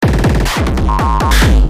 Tag: 140 bpm Glitch Loops Drum Loops 295.55 KB wav Key : Unknown